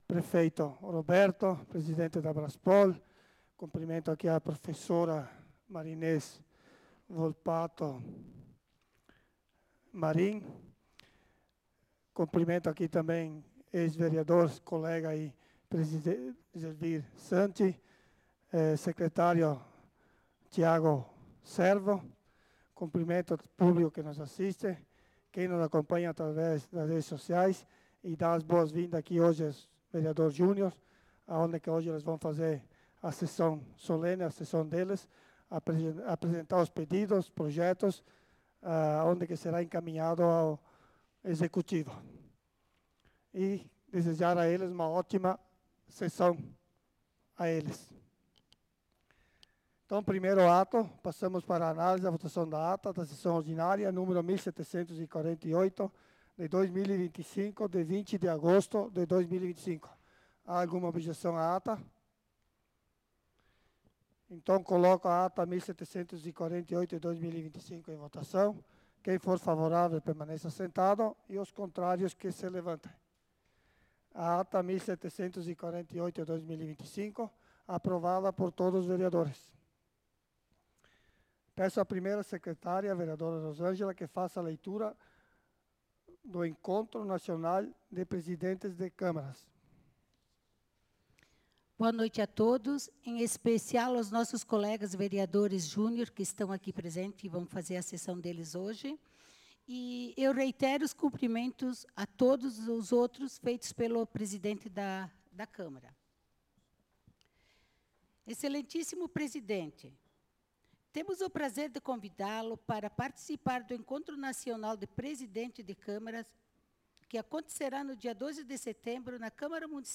Sessão Ordinária do dia 27/08/2025